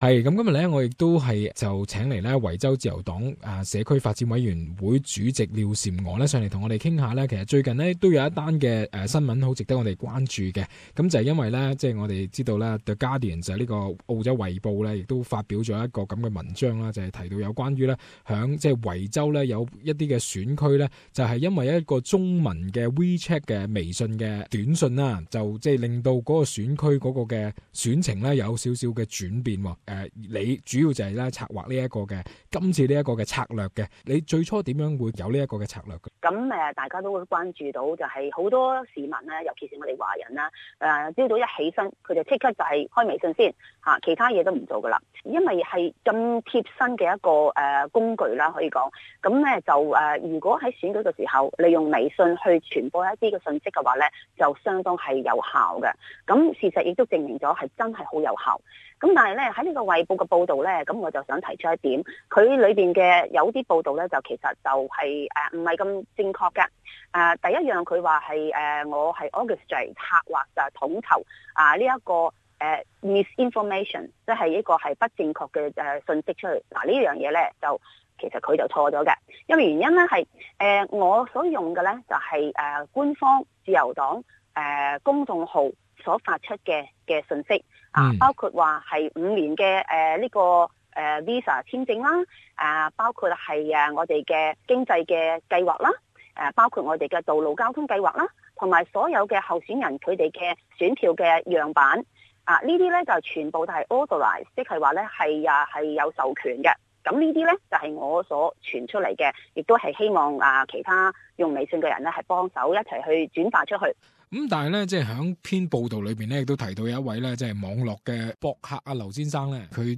A Guardian reports on How a Chinese-language social media campaign hurt Labor's election chances states that Gladys Liu, the Liberal party communities engagement committee chairwoman for Victoria, was behind a scheme on posting Chinese written pro-Liberal statement on social media that help liberal winning two Victorian seats. In an interview